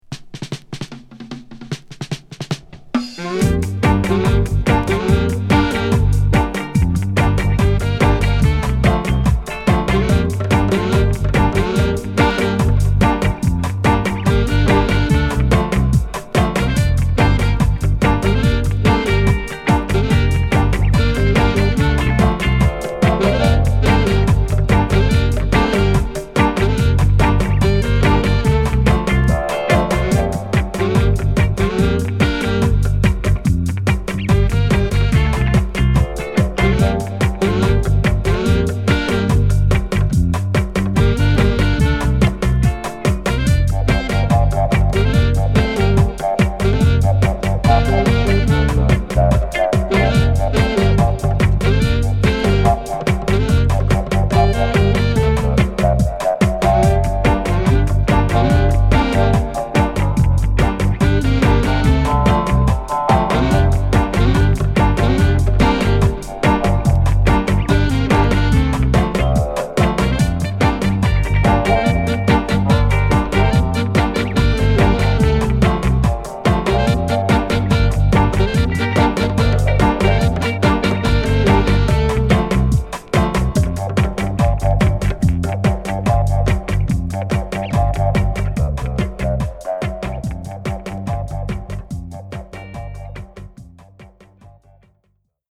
艶っぽいサックスが響きます！